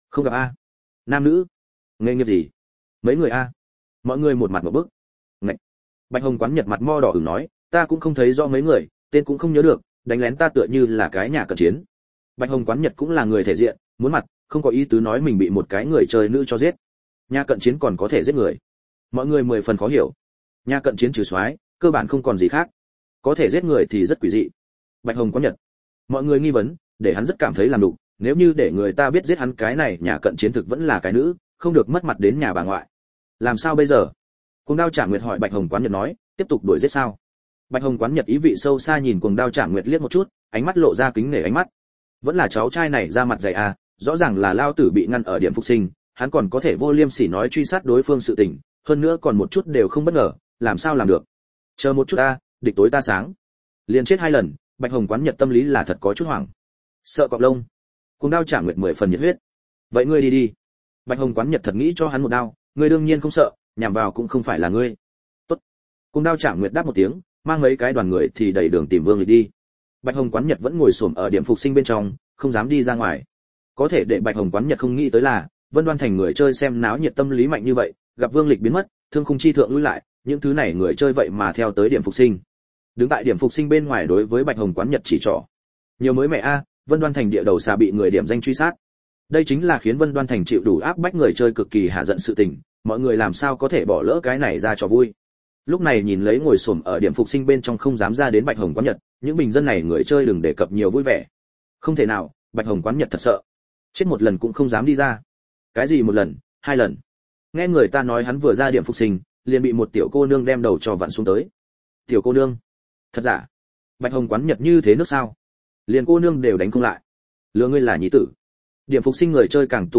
Võng Du Chi Bắt Đầu Thu Hoạch Được Trưởng Thành Thiên Phú Audio - Nghe đọc Truyện Audio Online Hay Trên AUDIO TRUYỆN FULL